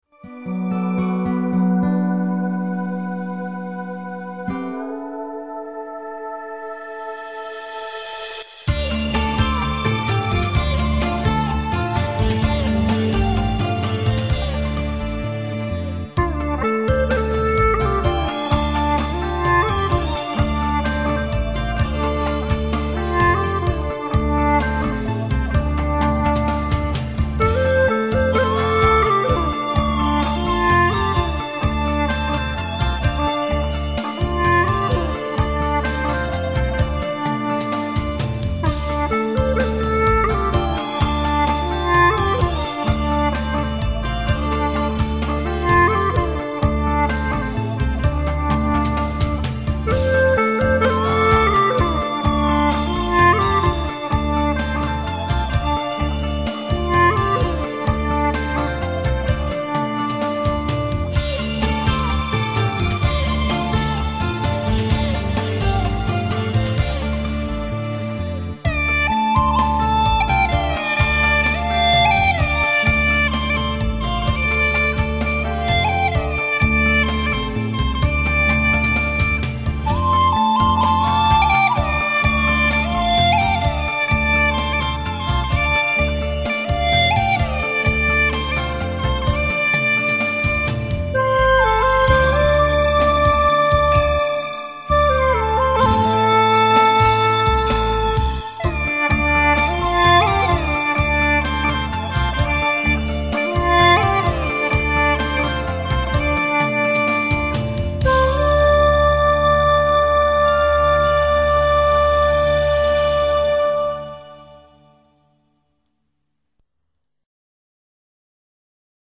纳西族民歌 演奏
调式 : F 曲类 : 民族